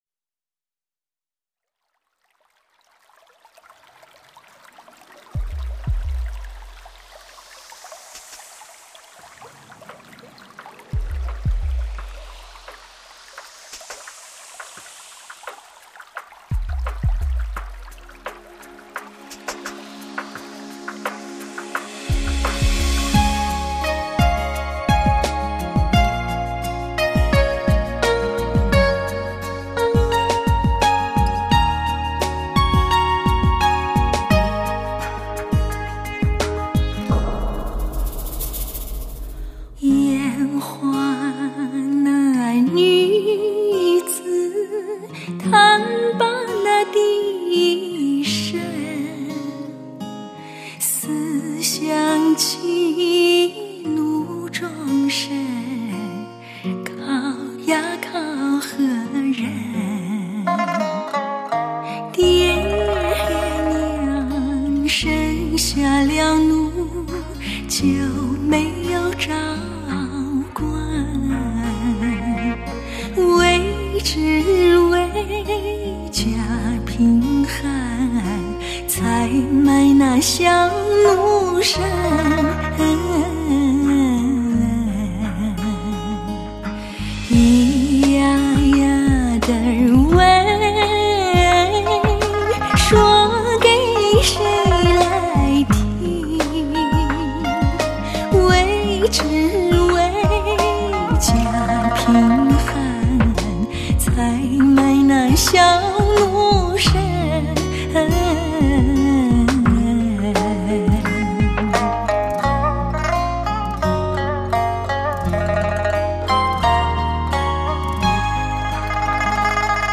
高清耐听。
作为风格，可谓一旧一新，一中一西结合。